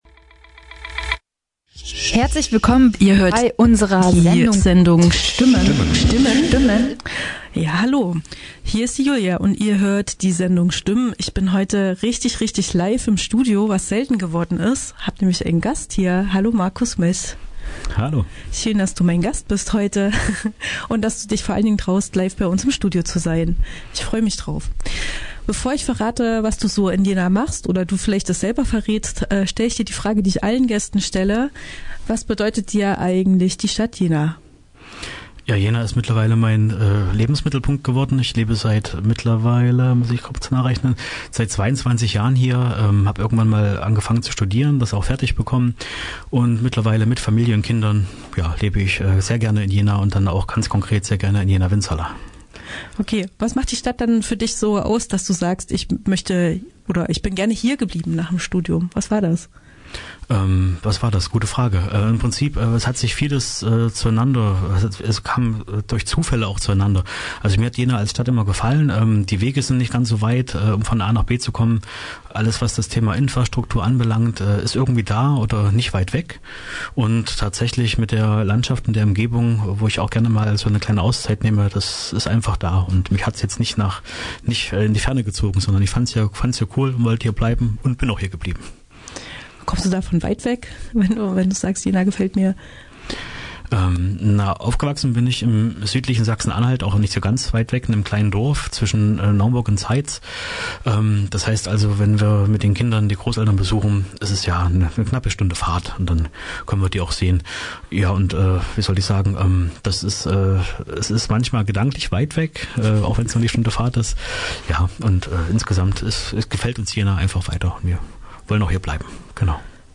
Ortsteilbürgermeister von Winzerla: Markus Meß